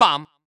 baBumBumBum_Close4.wav